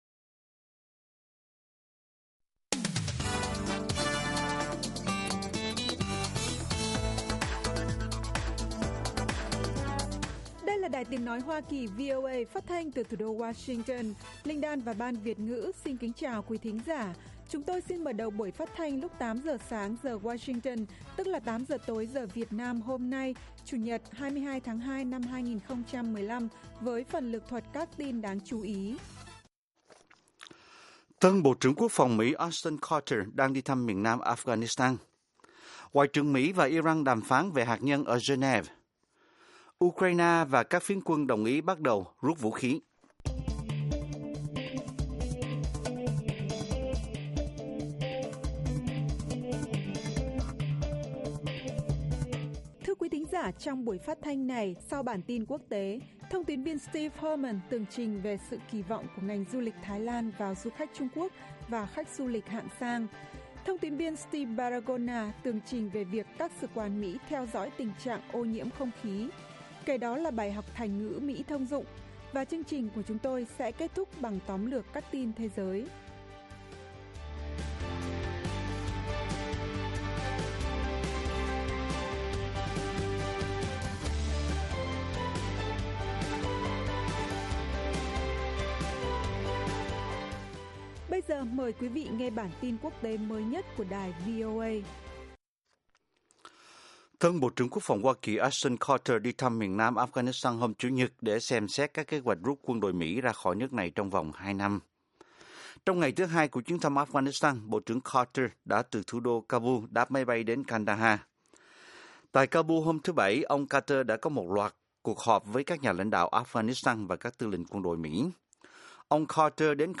Tin tức luôn cập nhật, thời sự quốc tế, và các chuyên mục đặc biệt về Việt Nam và thế giới. Các bài phỏng vấn, tường trình của các phóng viên VOA về các vấn đề liên quan đến Việt Nam và quốc tế.